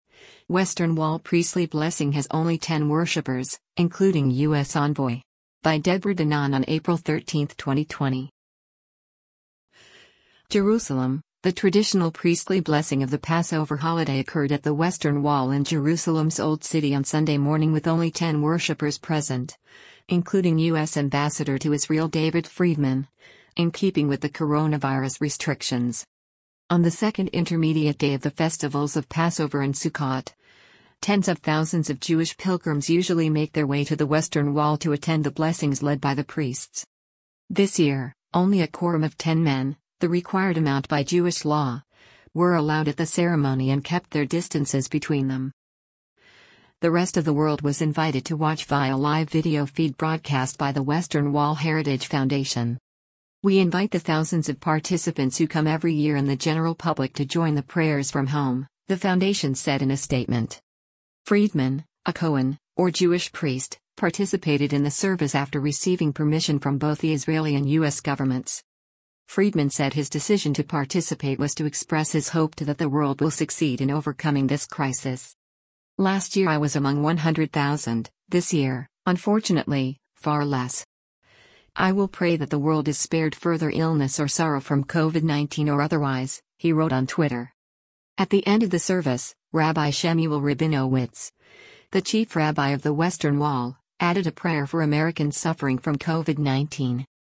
Western Wall Priestly Blessing Has Only 10 Worshipers, Including U.S. Envoy
JERUSALEM – The traditional priestly blessing of the Passover holiday occurred at the Western Wall in Jerusalem’s Old City on Sunday morning with only 10 worshipers present, including U.S. ambassador to Israel David Friedman, in keeping with the coronavirus restrictions.